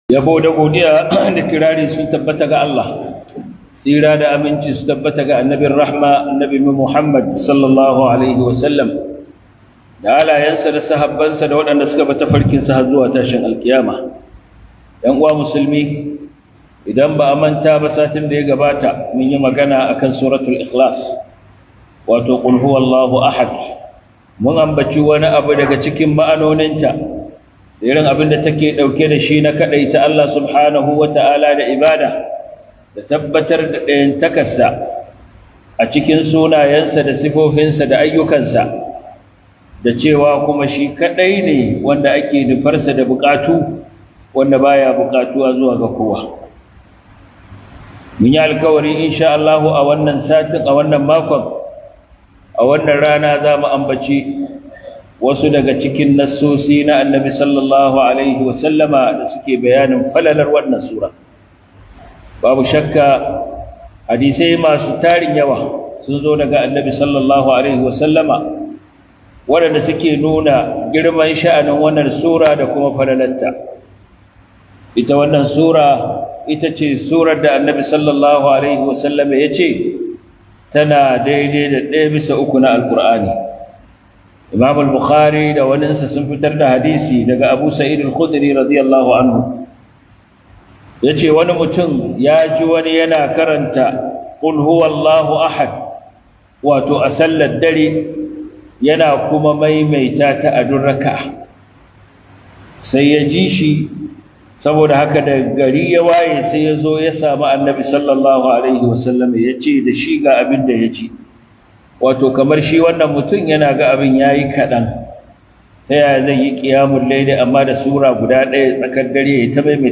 Suratul Ikhlas - HUDUBA